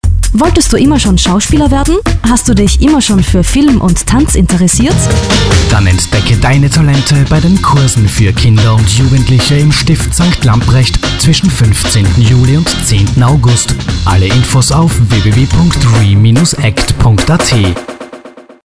Radiospot zu Sommerspiel 2007 SPOT - Radio Soundportal R�ckblick zu Sommerspiel 2006 MOVIE (R�ckblick Sommerspiel 2006) (Dateigr��e ca. 6MByte) zur�ck zur Startseite